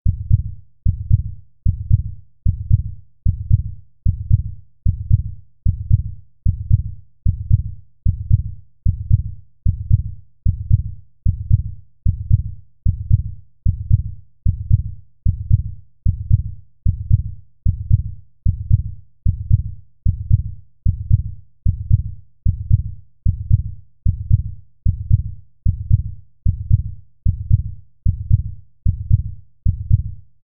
جلوه های صوتی
دانلود صدای ریتم قلب انسان از ساعد نیوز با لینک مستقیم و کیفیت بالا
برچسب: دانلود آهنگ های افکت صوتی انسان و موجودات زنده دانلود آلبوم صدای ضربان قلب انسان از افکت صوتی انسان و موجودات زنده